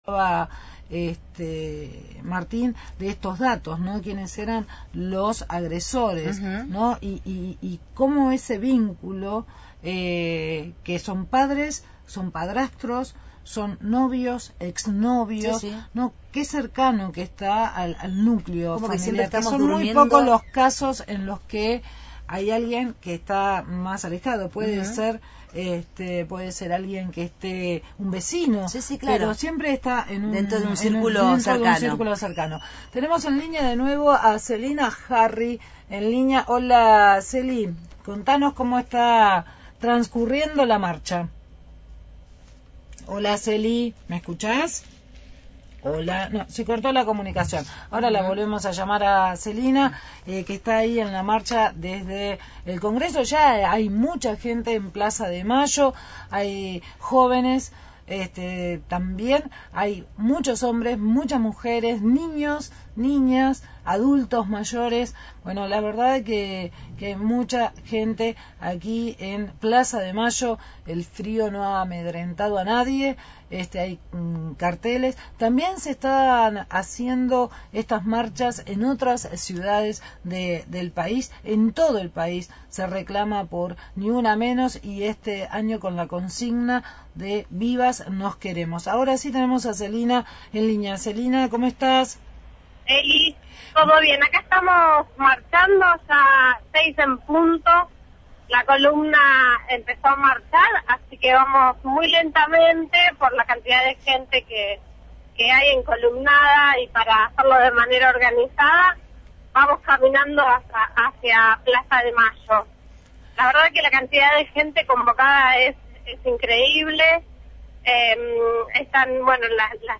desde la movilización «Ni una menos» en el Congreso de la Nación